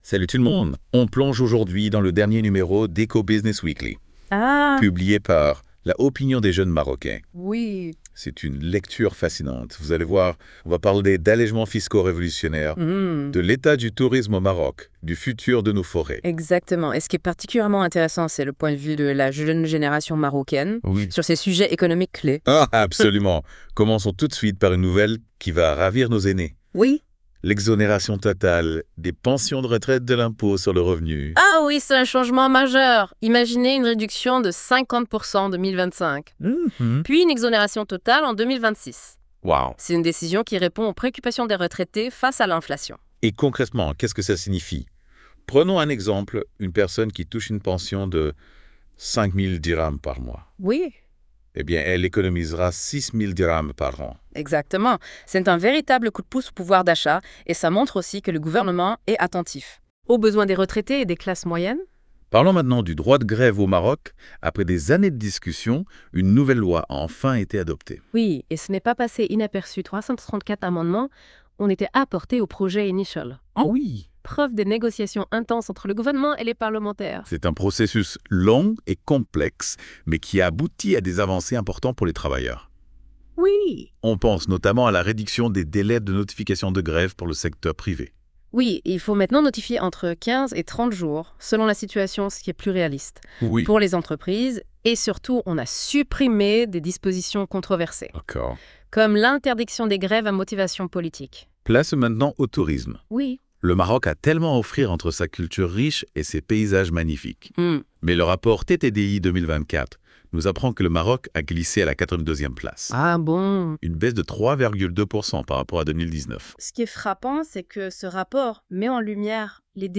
Les chroniqueurs de la Web Radio R212 ont lus attentivement l'hebdomadaire économique de L'ODJ Média et ils en ont débattu dans ce podcast ECO BUSINESS 09 Décembre 2024.wav (45.28 Mo) Questions suggérées : Quelles réformes clés améliorent la vie des Marocains ?